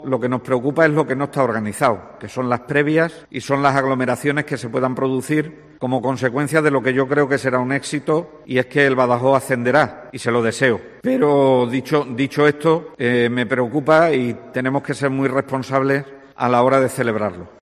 A preguntas de los periodistas en una rueda de prensa sobre la oferta de contratación a los médicos residentes, Vergeles ha recordado que el partido se disputará con público, con el aforo establecido, y cumpliendo el plan de contingencia presentado por el club pacense, por lo que en este sentido cree que el encuentro se disputará en un entorno "seguro".